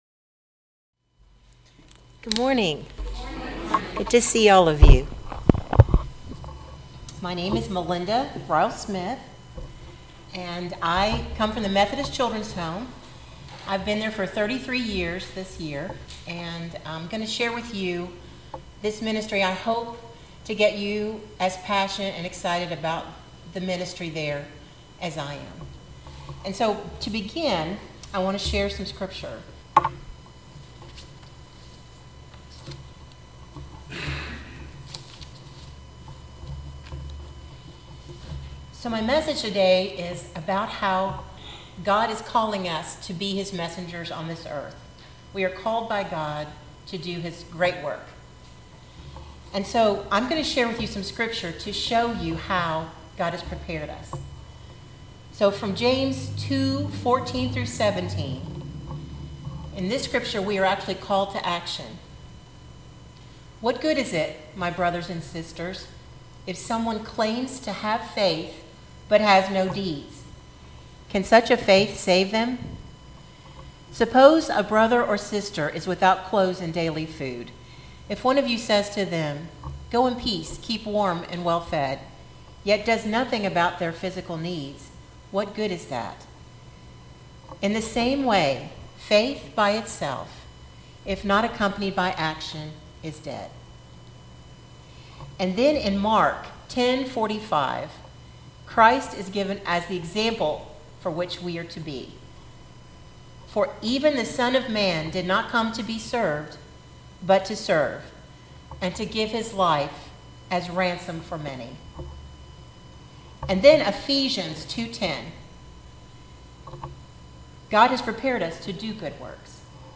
Sermon-Sent-by-God-audio-only.mp3